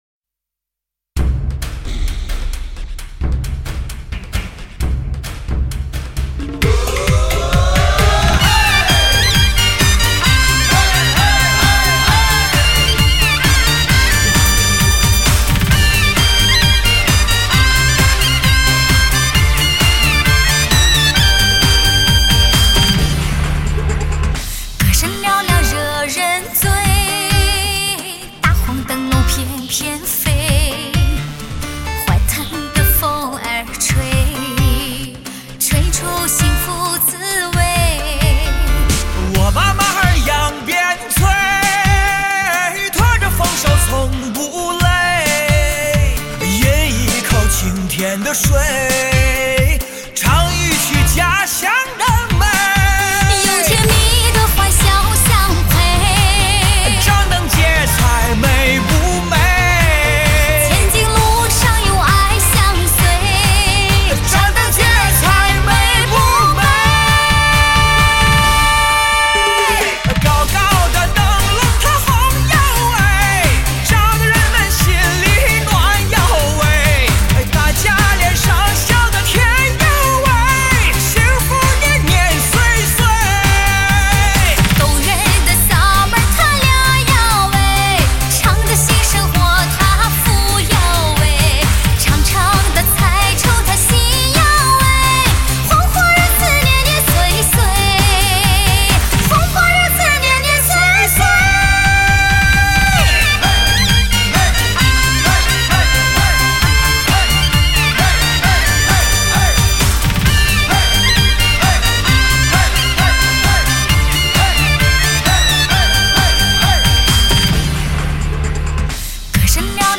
凭着拥有金属般的音色、闪电般的穿透力和极其罕见的高音，俨然成了央视节目的常客。
低音质